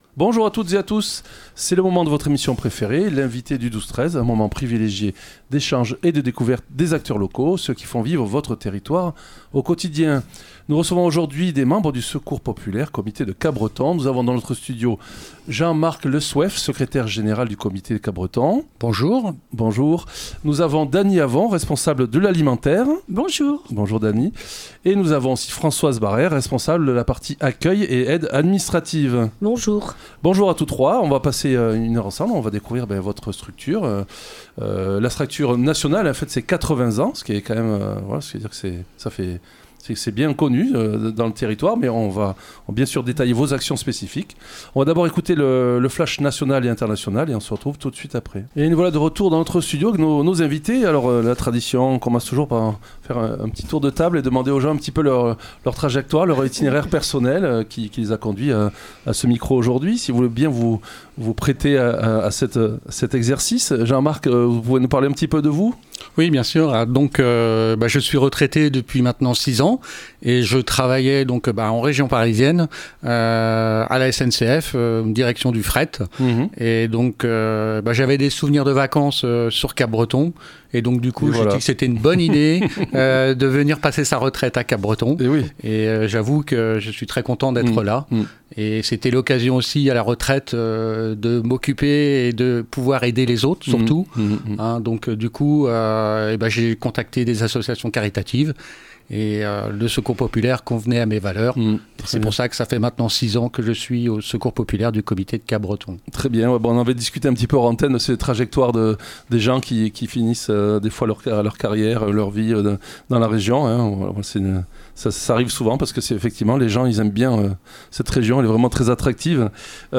L'invité(e) du 12-13 de Soustons recevait aujourd'hui des membres du Secours populaire, comité de Capbreton.